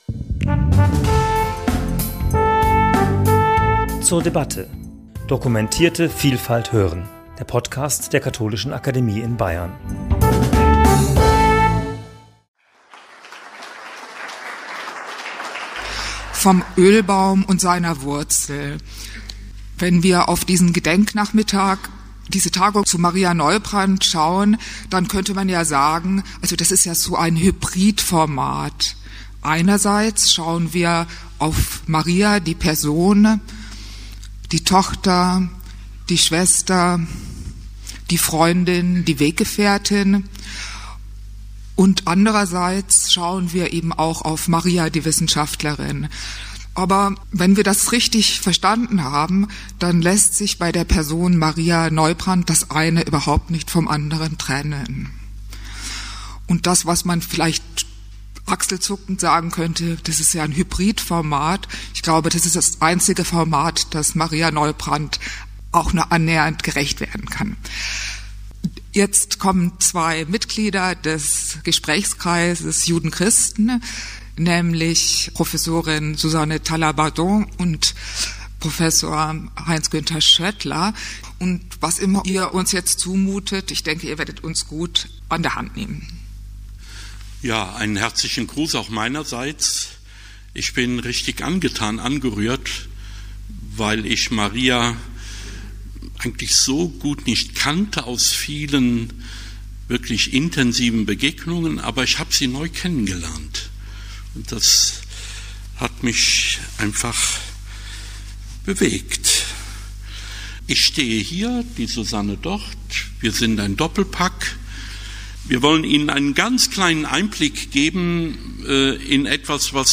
Podium zum Thema 'Vom Ölbaum und seiner Wurzel - Das Verhältnis von Judentum und Christentum nach Römer 9–11' ~ zur debatte Podcast